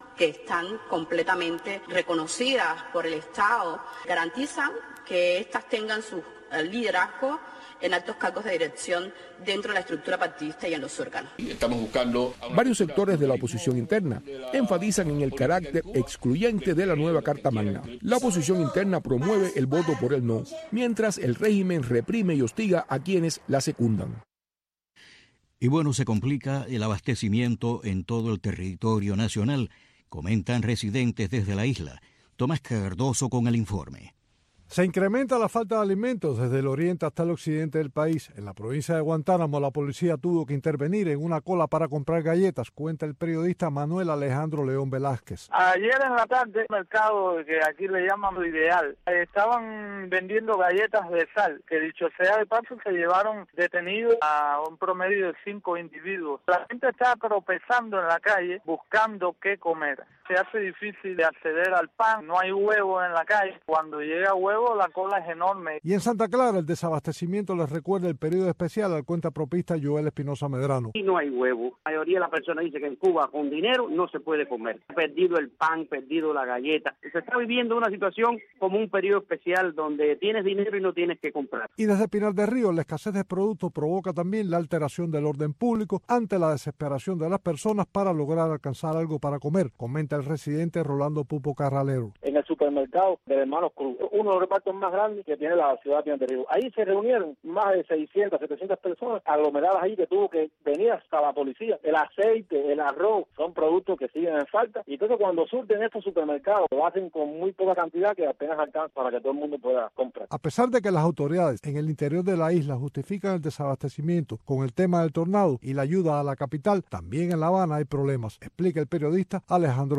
Programa humanista, preservador de buenas costumbres, reflexivo, aderezado con música y entrevistas. Las artes, el deporte, la ciencia, la política, e infinidad de tópicos, caben en este programa que está diseñado para enaltecer nuestras raíces, y para rendir tributo a esa bendita palabra que es Familia.